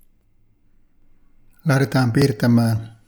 Crack sound problem, when recording speaking
There will NOT include any crack noise during that. Only when speaking. Like electrical spike is that.
I attached a sample and that typical crack appears at 2.2 sec And it will destroy my recordings.